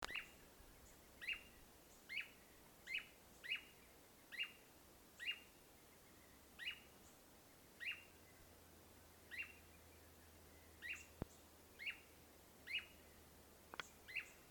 Tangará (Chiroxiphia caudata)
Nome em Inglês: Blue Manakin
Localidade ou área protegida: Parque Provincial Cruce Caballero
Condição: Selvagem
Certeza: Observado, Gravado Vocal